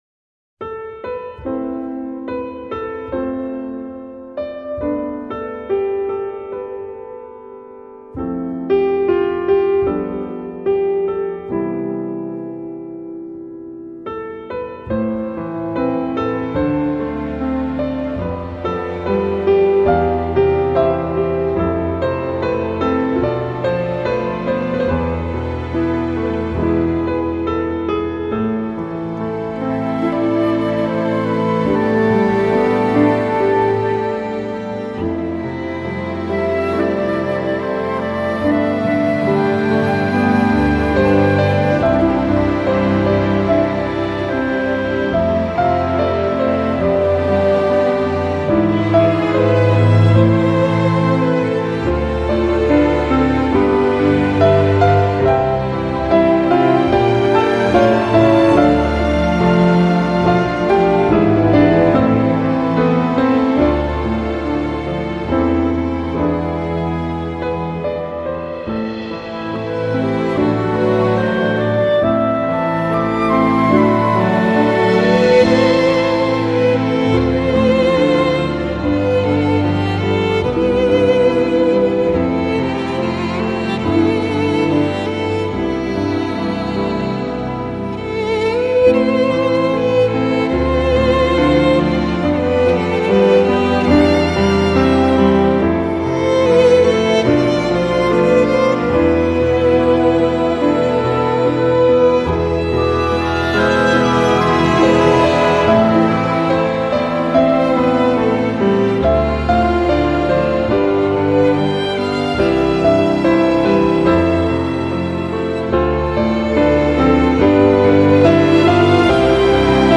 ピアノ、ストリングス バージョン
钢琴和弦乐版